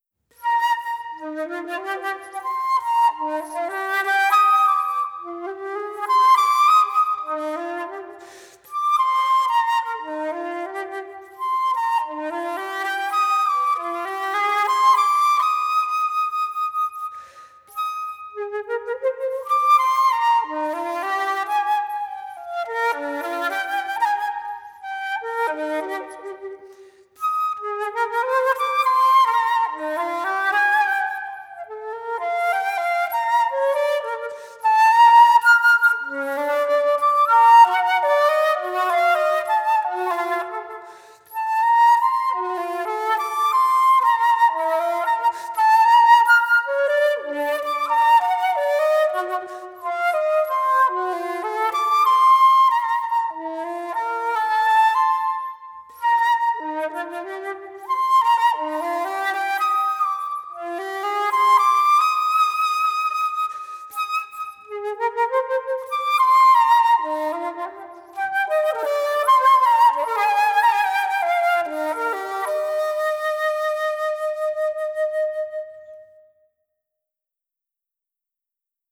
The melody needs to sing with the lower notes sounding like another voice.